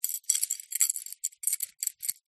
Звук бряцающих медалей одна о другую